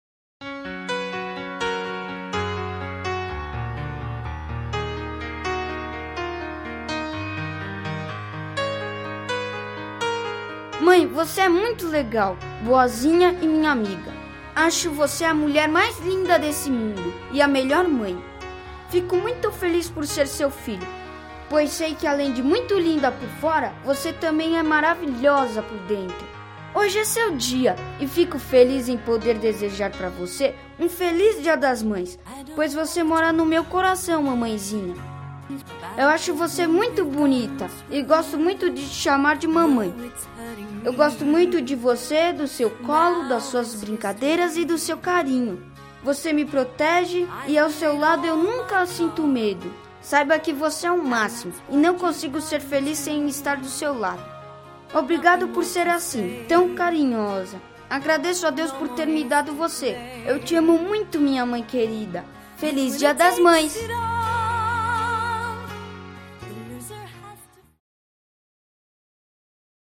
20-Voce-e-linda-filho-crianca-M.mp3